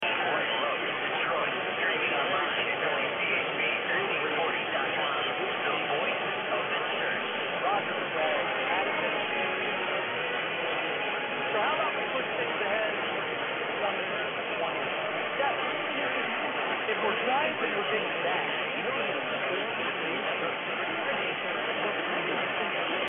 Two Finnish radio enthusiasts captured the signal of 1 kW Michigan AM station 1340 WCHB(AM) from a remote cabin above the Arctic Circle, some 4,200 miles away.
Their setup includes an over 4,000-foot-long Beverage antenna, carefully oriented to optimize reception from North and South America. They use a Perseus SDR receiver to capture the signals.